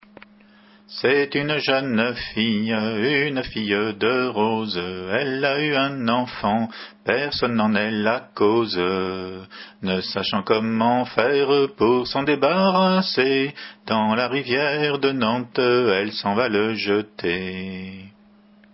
Hanter dro
Entendu au festival des "Assemblées gallèses" en juillet 91